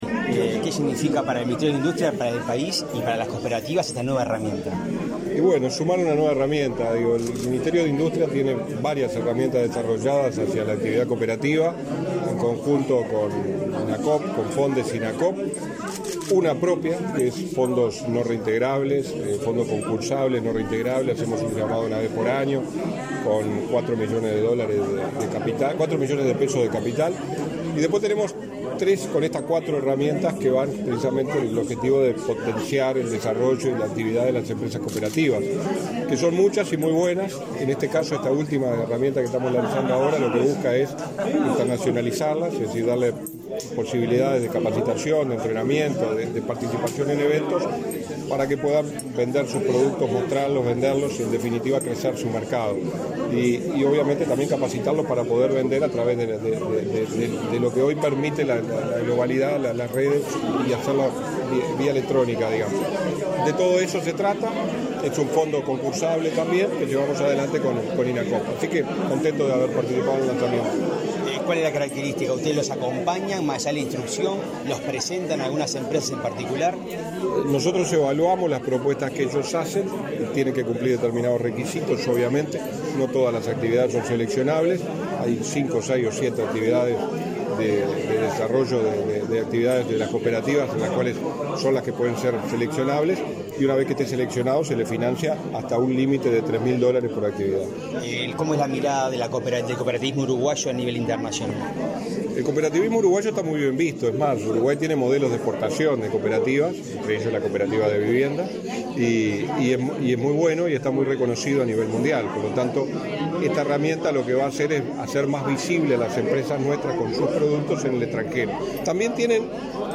Declaraciones a la prensa del subsecretario de Industria, Energía y Minería, Walter Verri
Tras participar en la presentación de la herramienta “Cooperativas integradas al mundo”, para facilitar la inserción internacional de cooperativas y organizaciones de la economía social y solidaria, este 3 de mayo, el subsecretario de Industria, Energía y Minería, Walter Verri, realizó declaraciones a la prensa.
Verri prensa.mp3